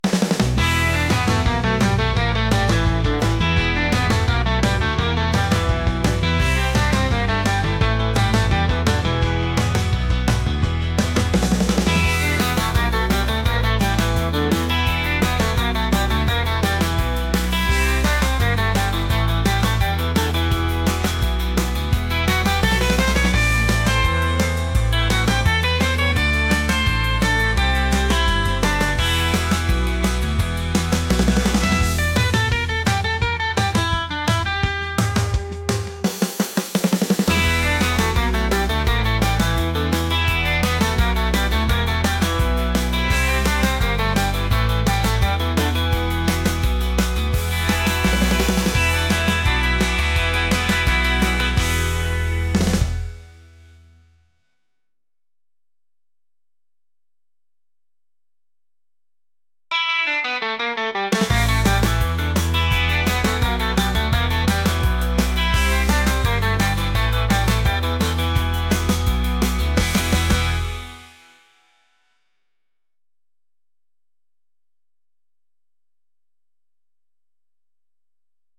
upbeat | retro | rock